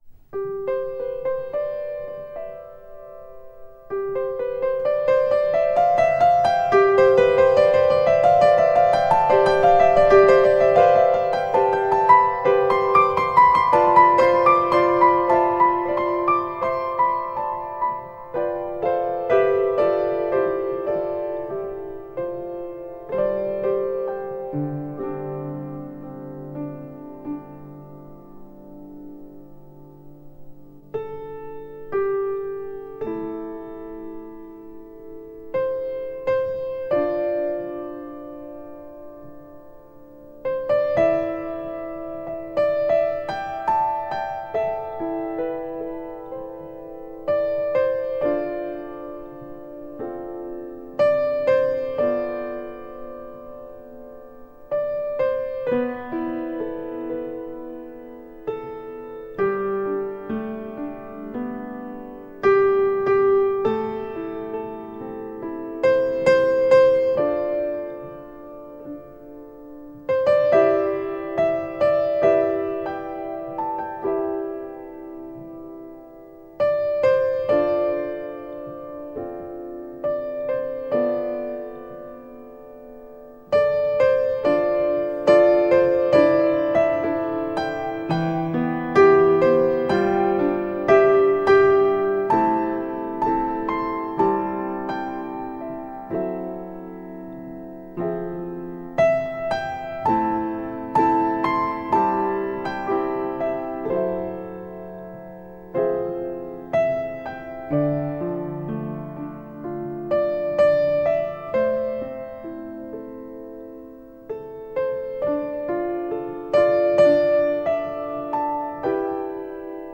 清澈幻美的音乐旋律 华语音乐的浪漫声音